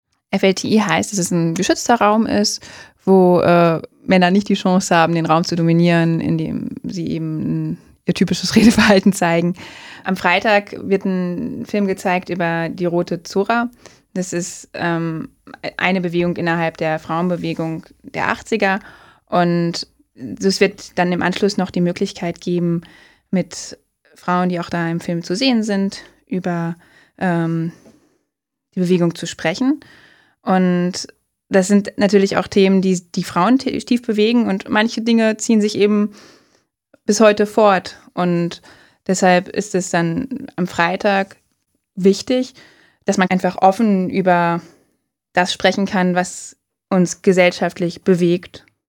Oktober 2019 – Interview im Weckruf